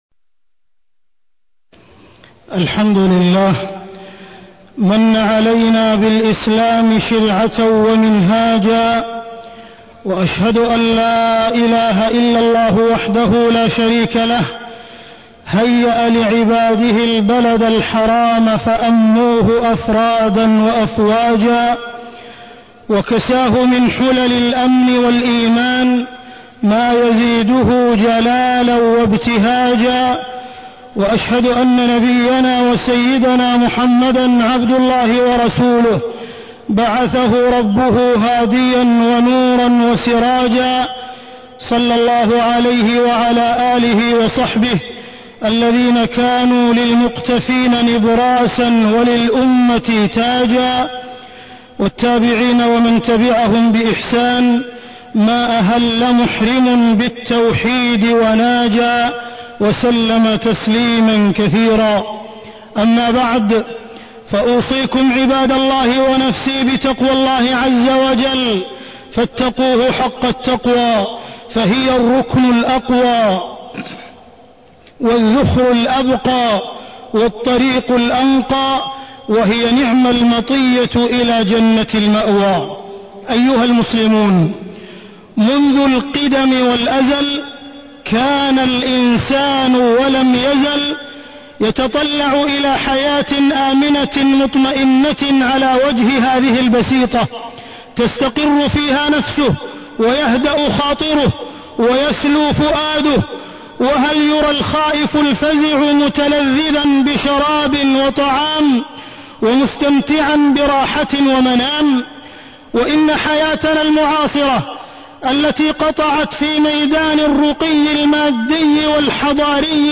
تاريخ النشر ٢٨ ذو القعدة ١٤٢٣ هـ المكان: المسجد الحرام الشيخ: معالي الشيخ أ.د. عبدالرحمن بن عبدالعزيز السديس معالي الشيخ أ.د. عبدالرحمن بن عبدالعزيز السديس أمن البلد الحرام